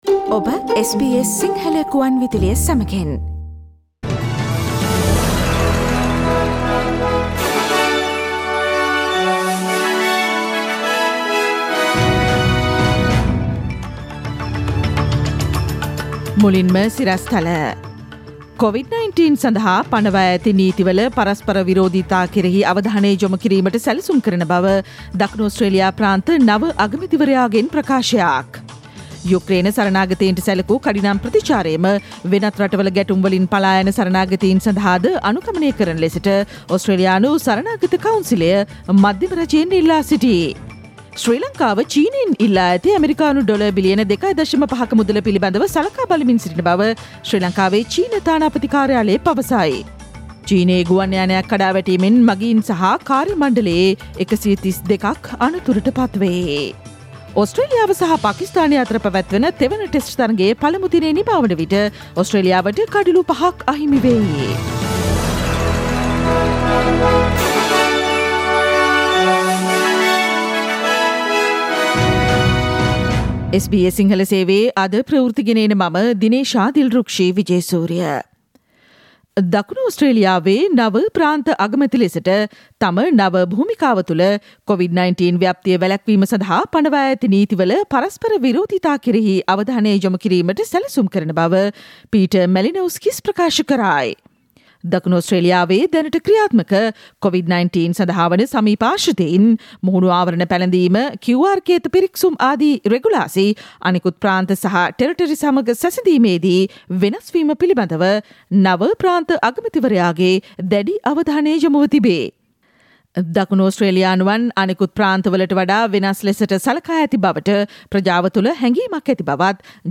ඉහත චායාරූපය මත ඇති speaker සලකුණ මත click කොට මාර්තු 22 වන අඟහරුවාදා SBS සිංහල ගුවන්විදුලි වැඩසටහනේ ප්‍රවෘත්ති ප්‍රකාශයට ඔබට සවන්දිය හැකියි.